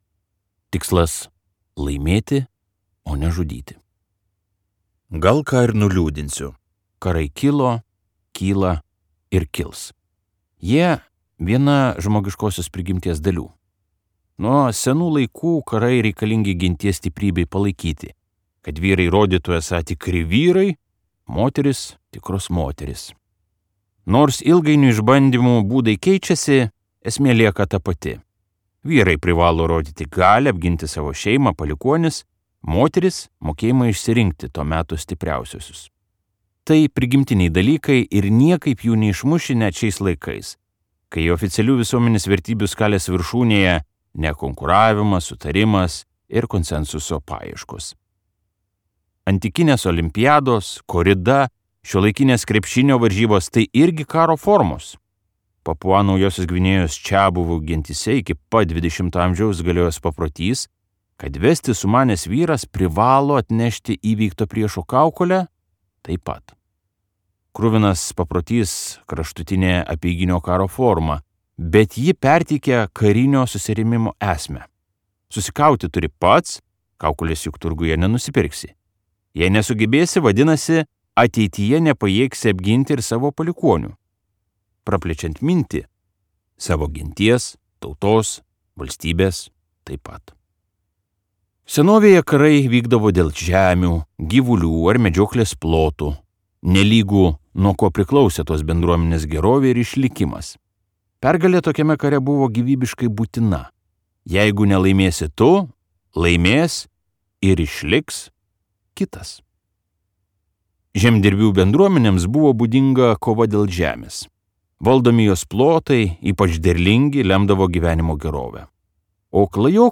Nuo seniausių laikų iki dabarties | Audioknygos | baltos lankos
Skaityti ištrauką play 00:00 Share on Facebook Share on Twitter Share on Pinterest Audio Karai ir taikos.